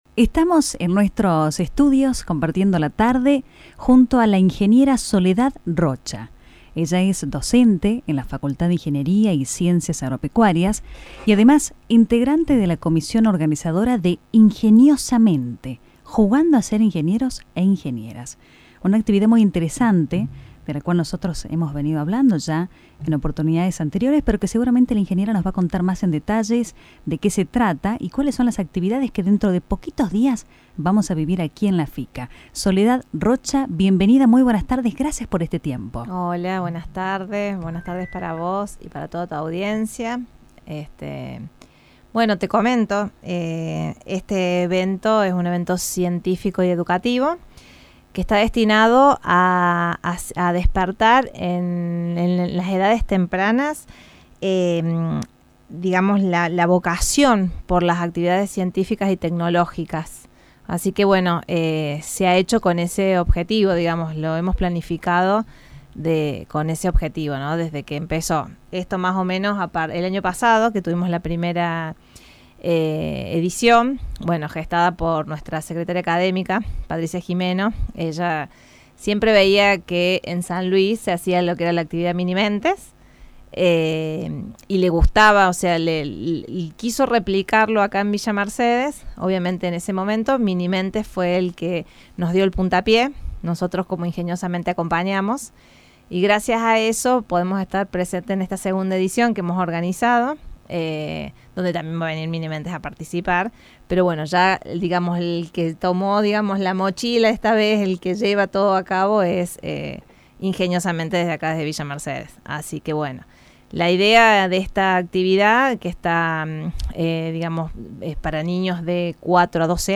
Entrevista realizada en el programa radial “La Quilla”